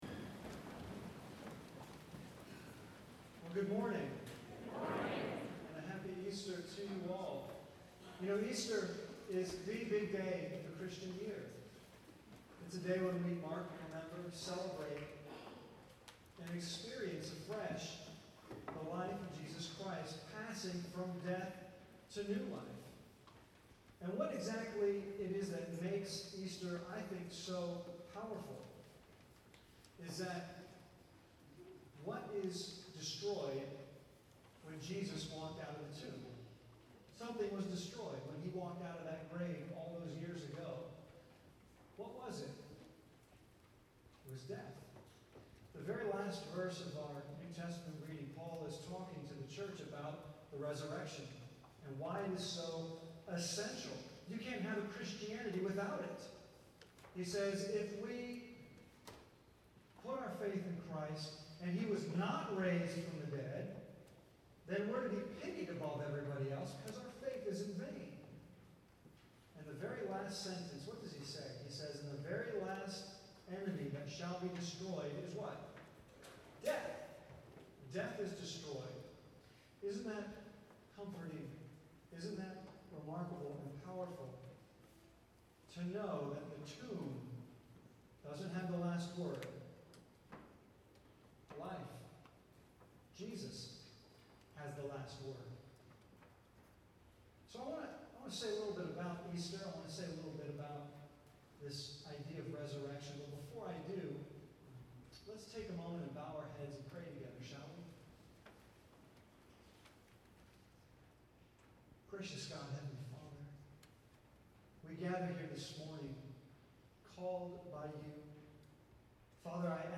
April 25, 2011 This Easter sermon, titled "Don't Go Back", focuses on the end of our Journey with Jesus into Jerusalem through the events of...